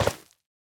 Minecraft Version Minecraft Version latest Latest Release | Latest Snapshot latest / assets / minecraft / sounds / block / netherwart / step3.ogg Compare With Compare With Latest Release | Latest Snapshot
step3.ogg